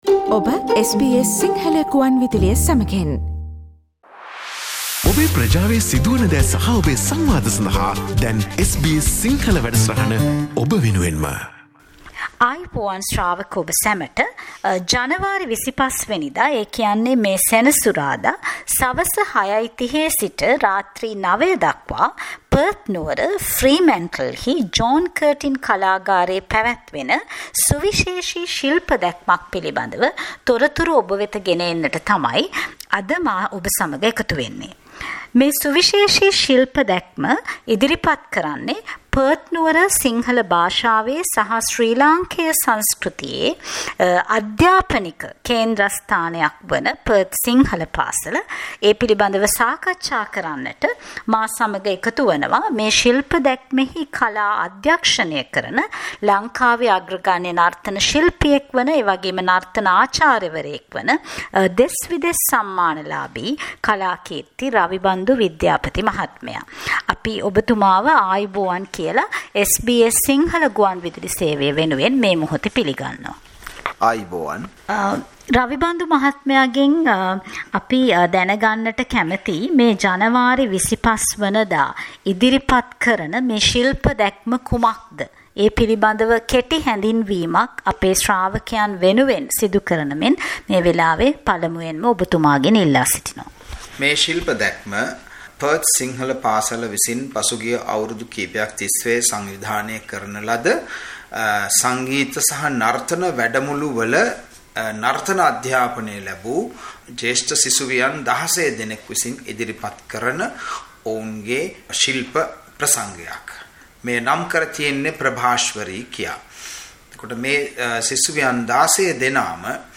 Dance performance of Sri Lankan children in Perth Source: SBS Sinhala Radio